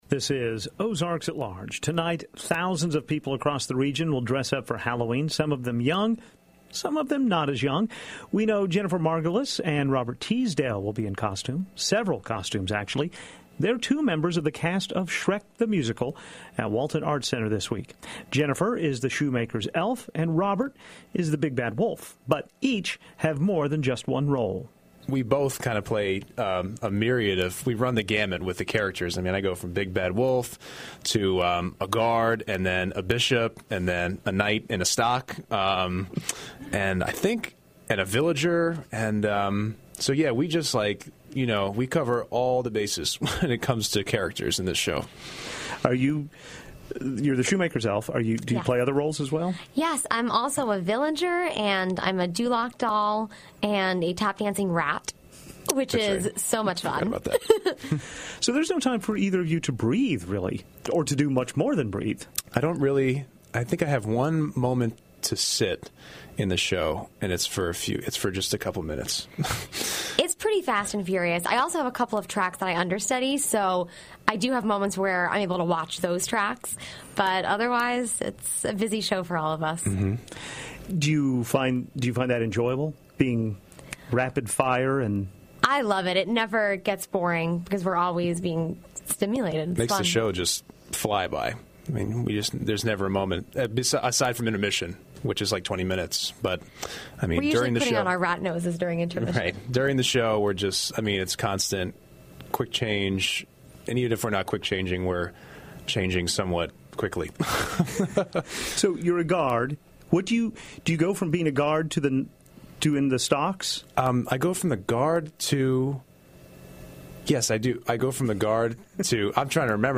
Shrek’s Big Bad Wolf, Shoemaker’s Elf Come to the Studio